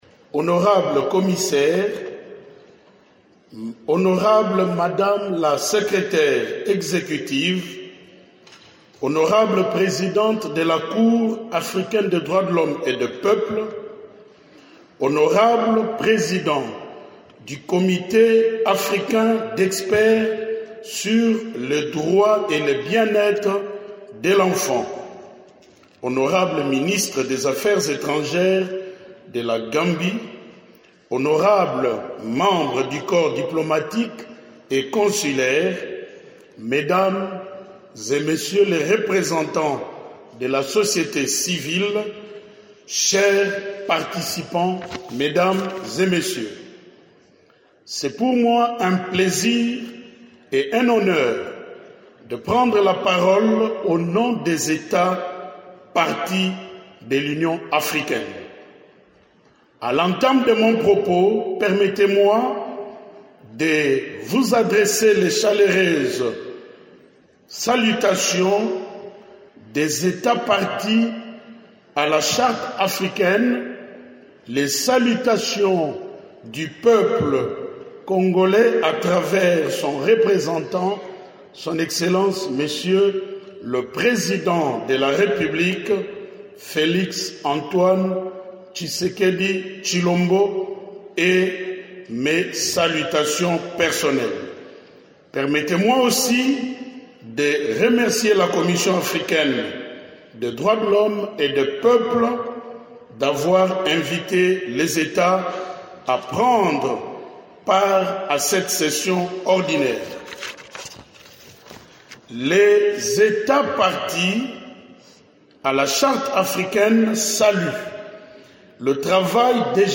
Le ministre des Droits humains représente la RDC, depuis mardi 21 octobre, à la 85e session de la Commission africaine des droits de l’homme et des Peuples (CADHP), à Banjul, en Gambie.